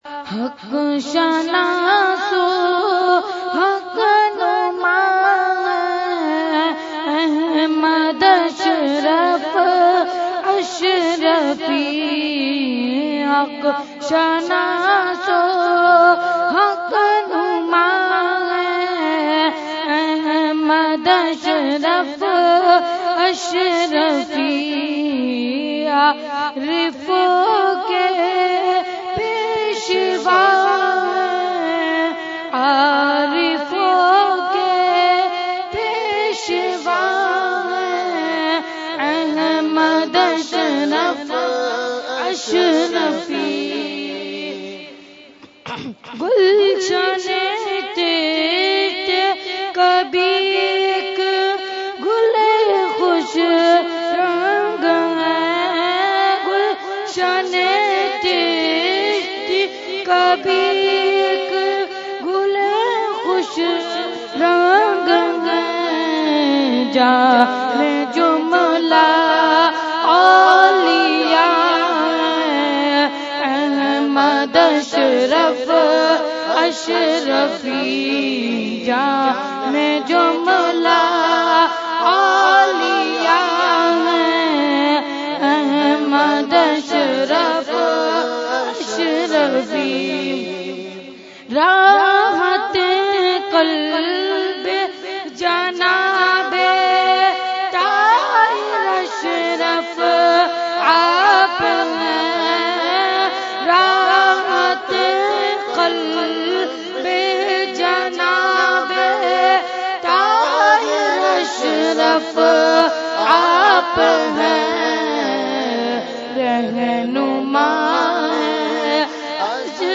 Category : Manqabat | Language : UrduEvent : Urs Ashraful Mashaikh 2018